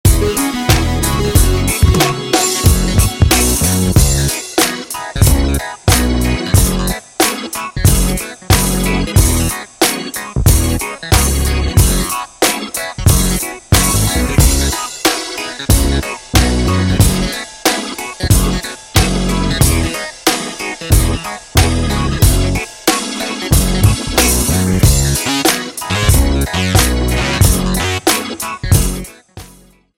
POP  (02.08)